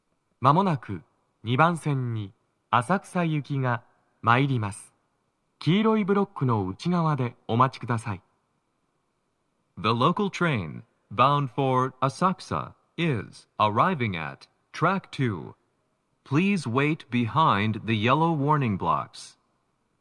スピーカー種類 TOA天井型
🎵接近放送
鳴動は、やや遅めです。